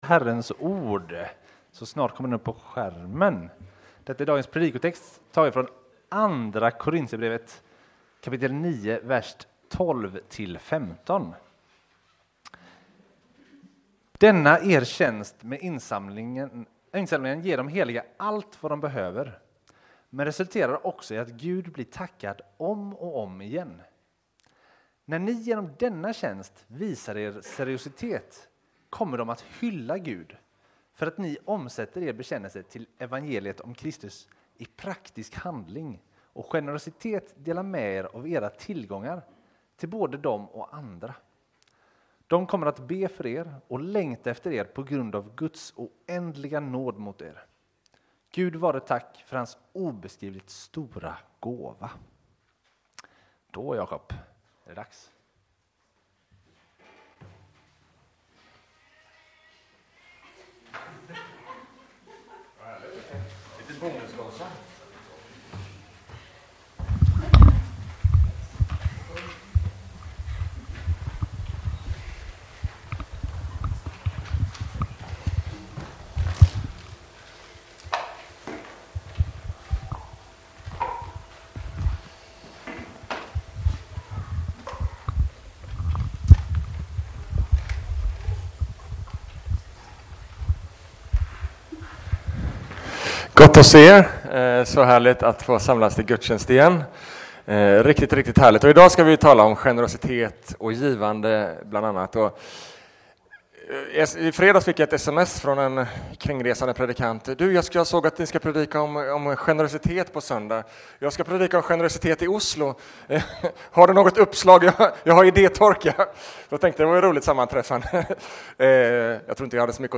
Sunday School Revisited Del 2: Rut – Sermons – CENTRO – Lyssna här